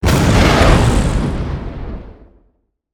explosion_medium.wav